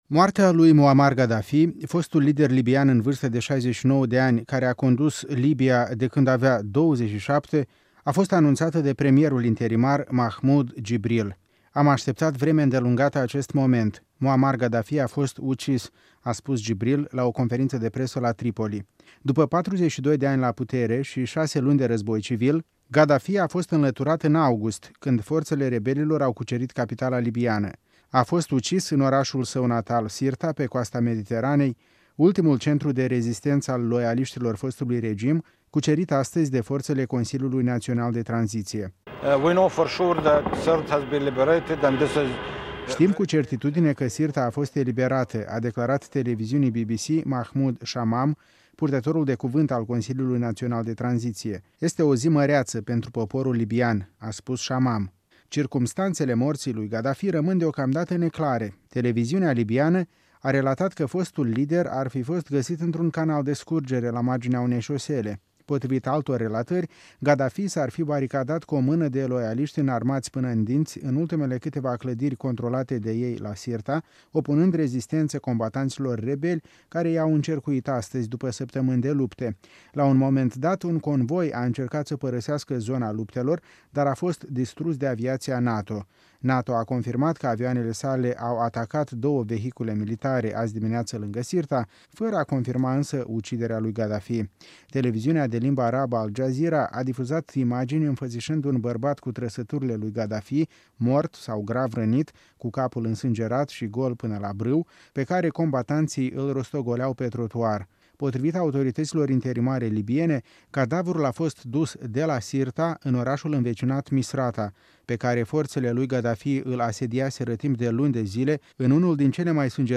rezumă relatările agențiilor de presă.